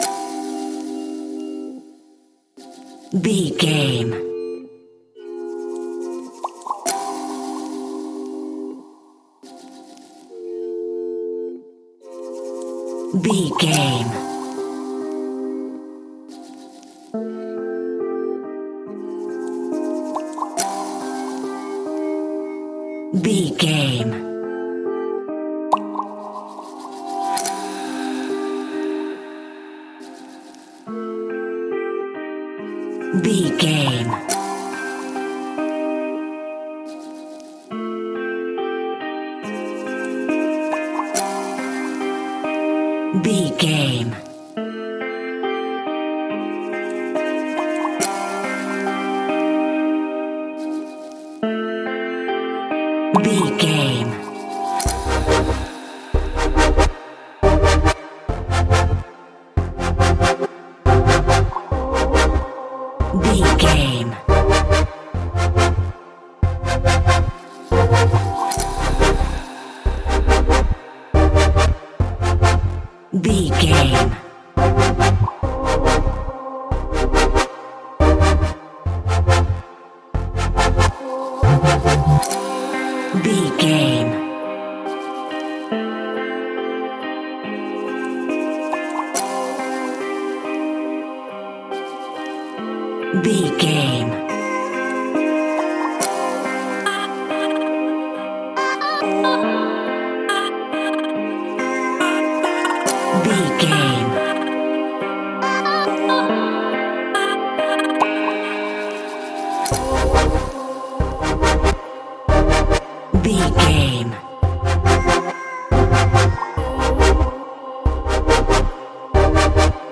Aeolian/Minor
Fast
dreamy
bouncy
energetic
electric guitar
synthesiser
percussion